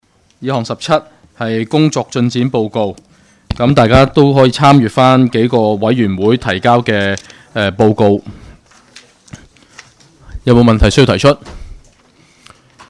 区议会大会的录音记录
油尖旺区议会第六次会议